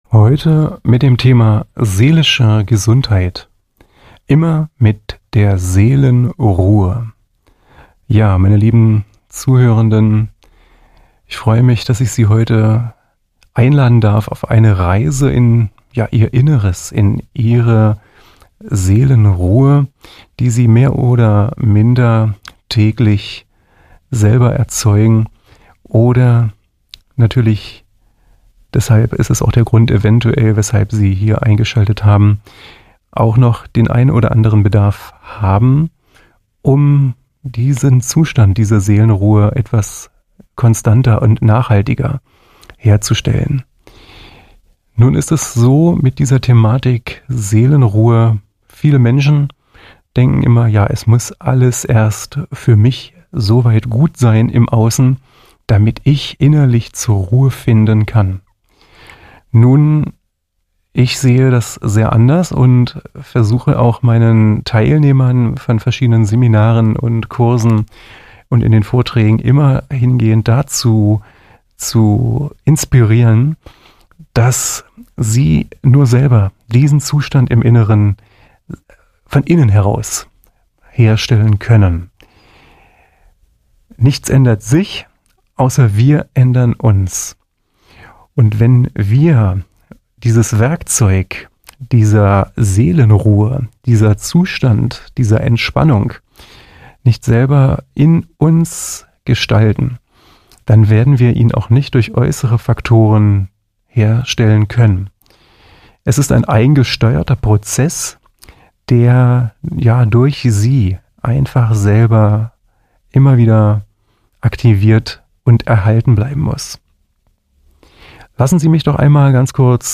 Darum geht es in Vorträgen und Gesprächen